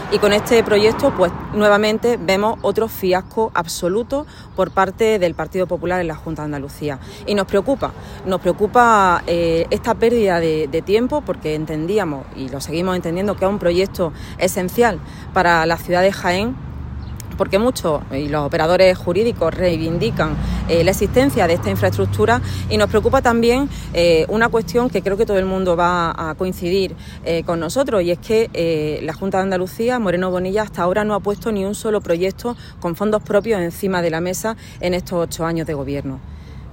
Por su parte, la viceportavoz del Grupo Socialista en el Ayuntamiento de Jaén, África Colomo, denunció que el PP “lo ha vuelto a hacer, ha vuelto a engañar a la ciudad”.